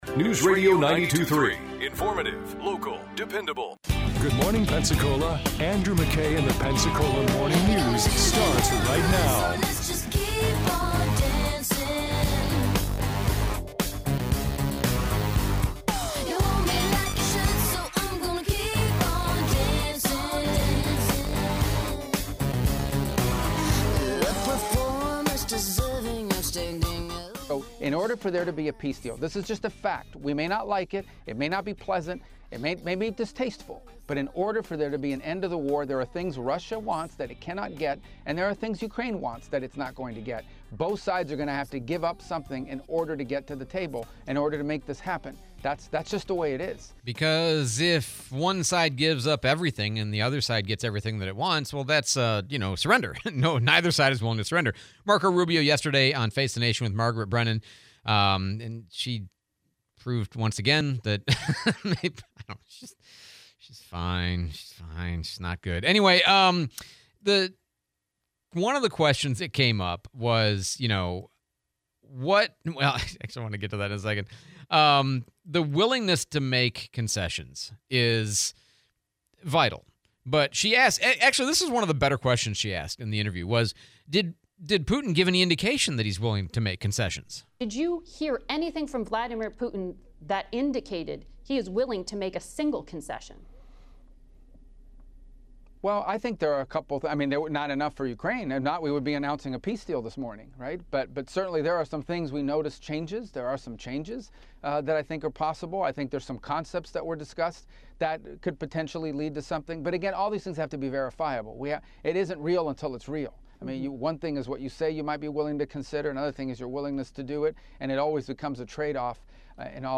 Ukraine negotiations, interview with Congressman Patronis